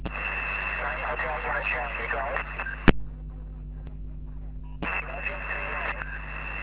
Meteor Scatter
But the activity was great, some pile-ups, QRM as usually, so I worked about 100 QSOs, all random SSB.
Used rig: TCVR R2CW, PA 500W, ant: 4x9el (North-East), 4x4el (West) and 4x4el (South).